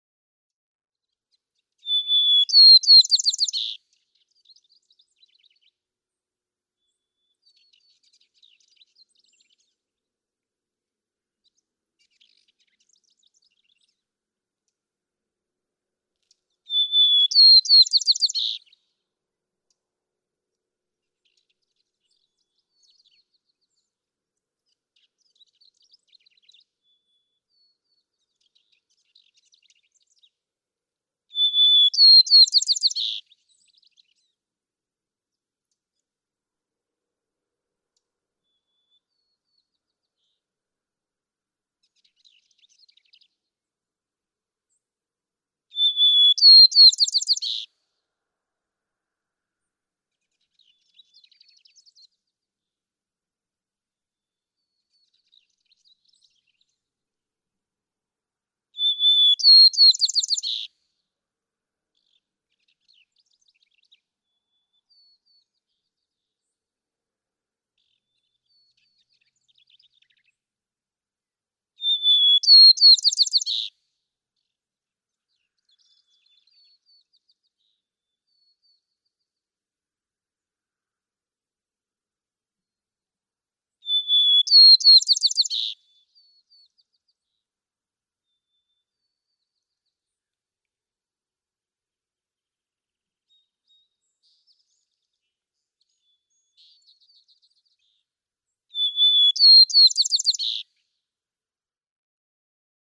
White-crowned sparrow
♫241, ♫242, ♫243—longer recordings from those three individuals
242_White-crowned_Sparrow.mp3